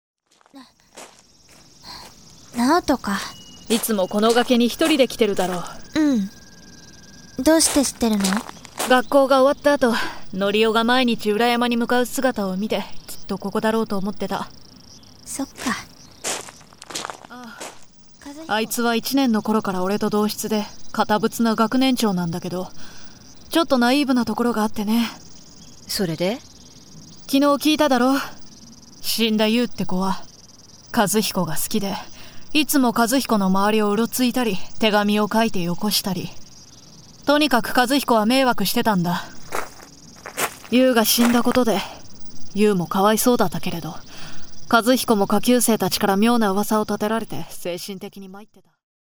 口調 兄貴肌っぽい？